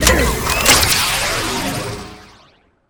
laser1.wav